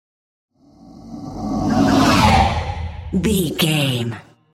Whoosh monster breath
Sound Effects
Atonal
scary
ominous
eerie
whoosh